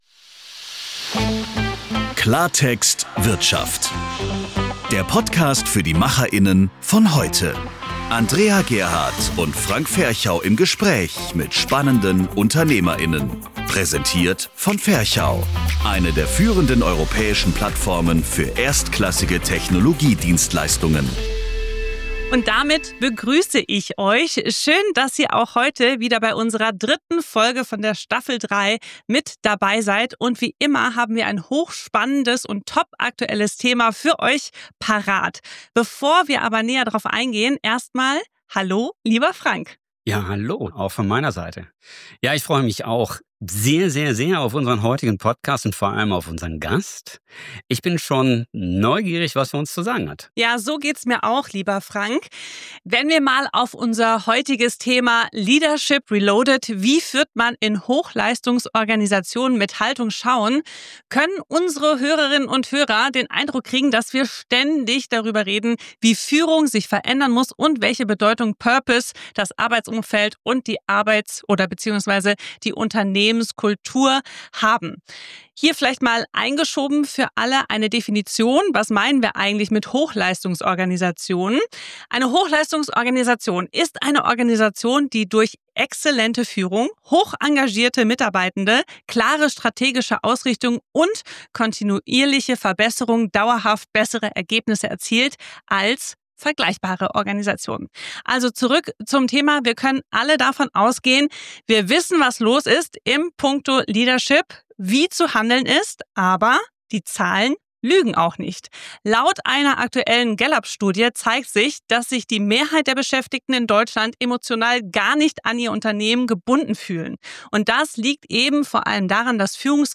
Ein Gespräch über Neugier, Zeit, Ehrlichkeit und warum Führung keine Frage hipper Begriffe, sondern konsequenter Haltung ist.